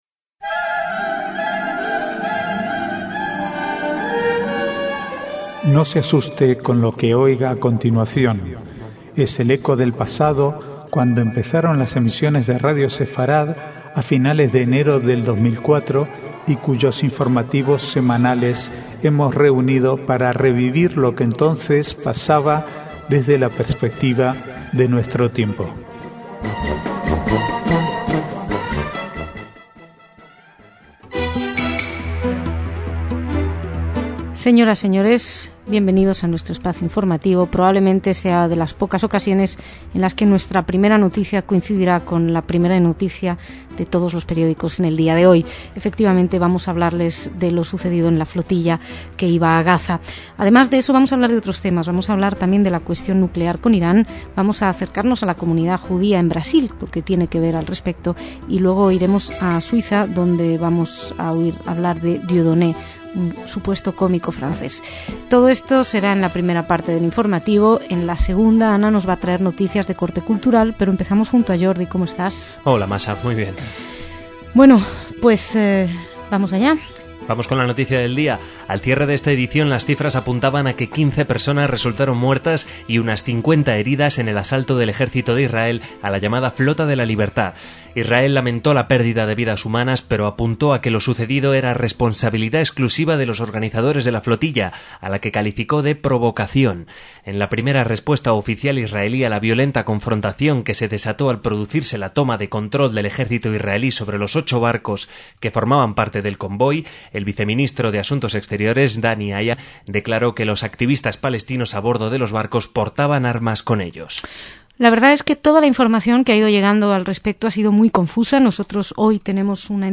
Archivo de noticias del 1 al 4/6/2010